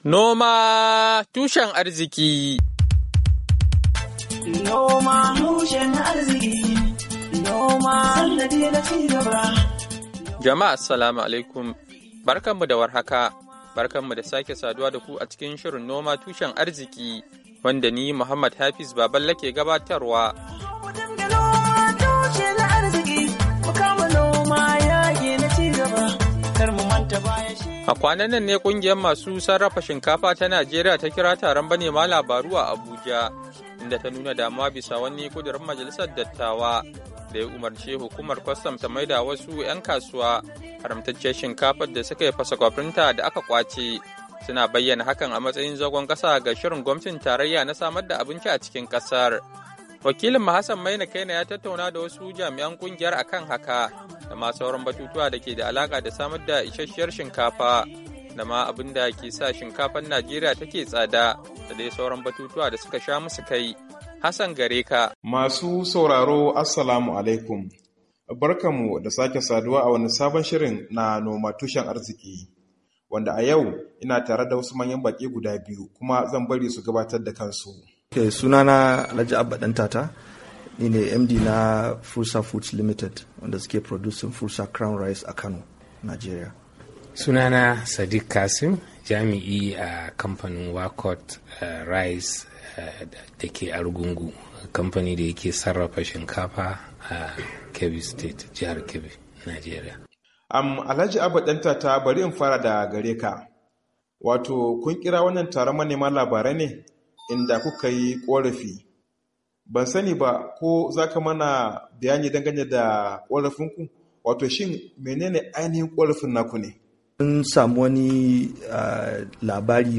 WASHINGTON D.C. —